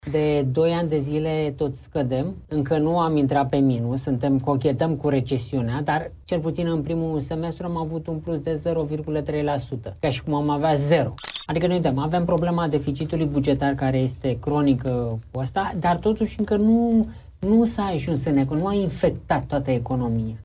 Situația a fost analizată într-o dezbatere cu tema „Cum pot fi relansate investițiile în România?”, la „Ziarul Financiar”.